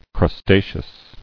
[crus·ta·ceous]